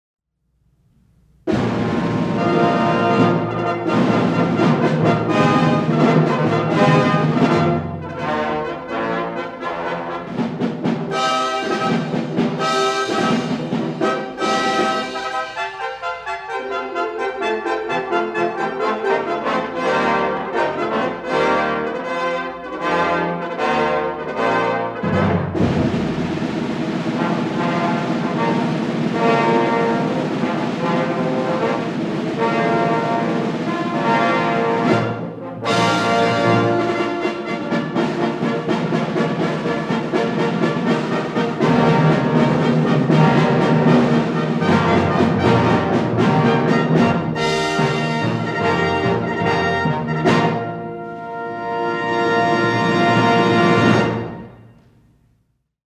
Concert Performance October 7, 1973
Audience nearly filled the house.
using a half-track, 10” reel-to-reel Ampex tape recorder
Armstrong Auditorium, Sunday at 4:00 PM